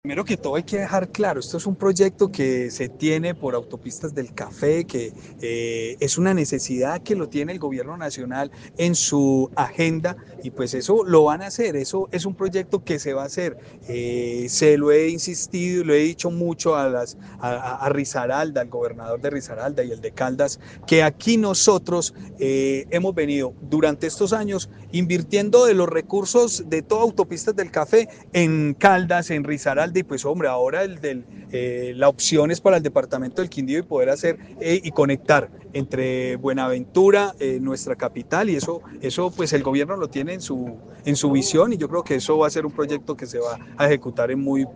Juan Miguel Galvis, gobernador del Quindío